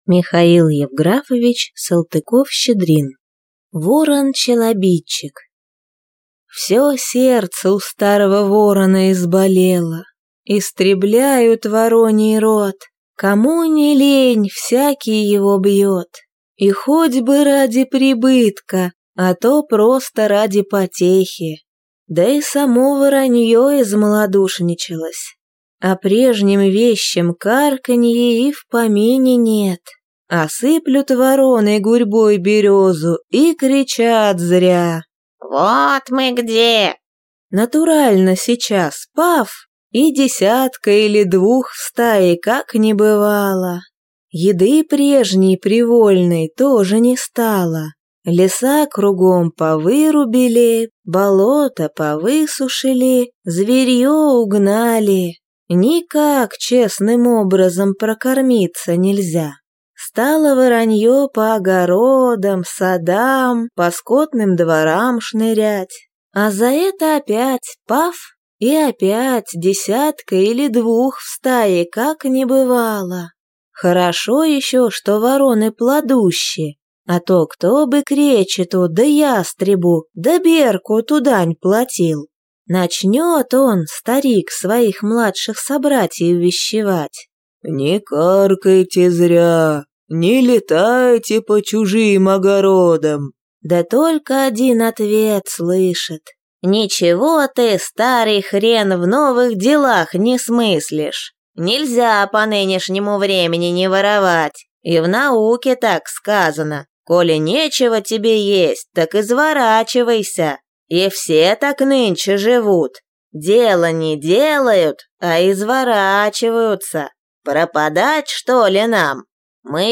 Аудиокнига
Жанр: Сказки